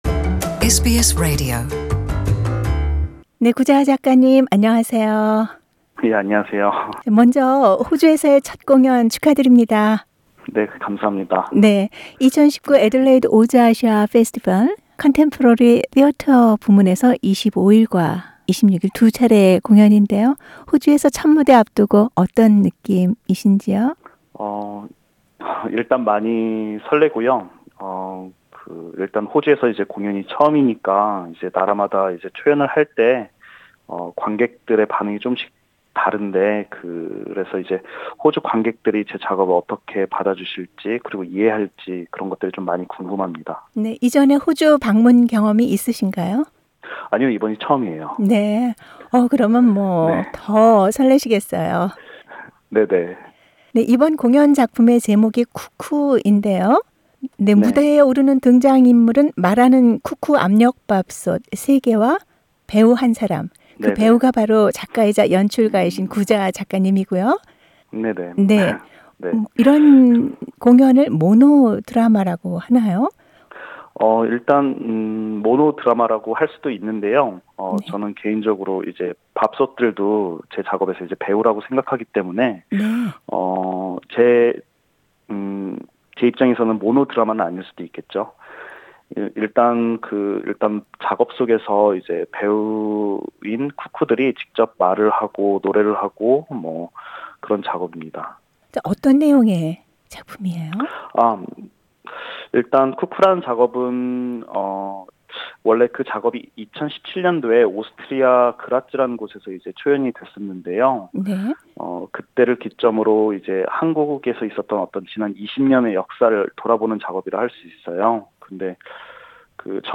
[컬처 인터뷰]